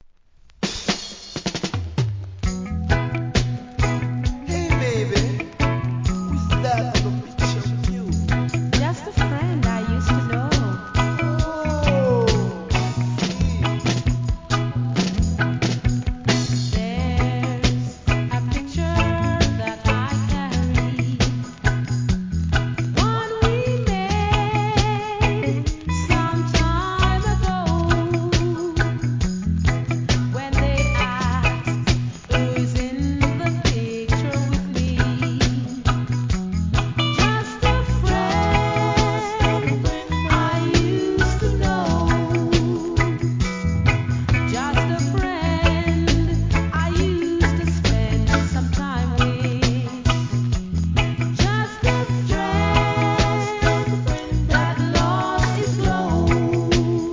REGGAE
ハートウォーミングなヴォーカルで♪ No. タイトル アーティスト 試聴 1.